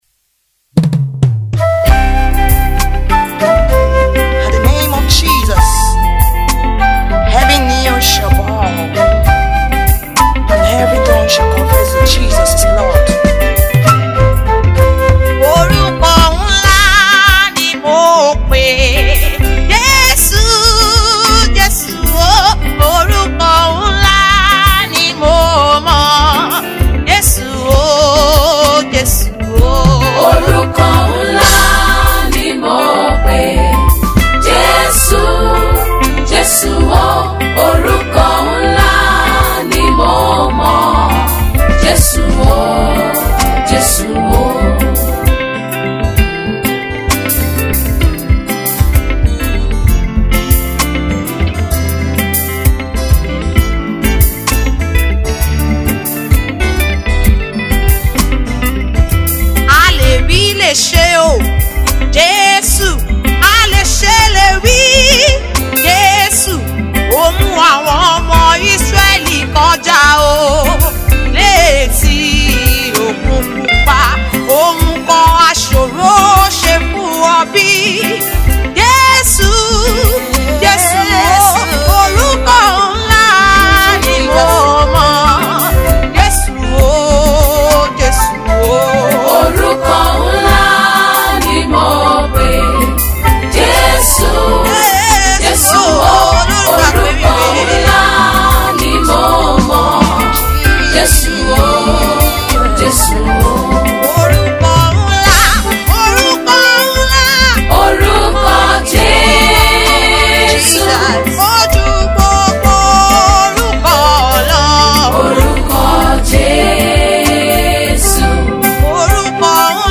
A fast raising talented gospel singer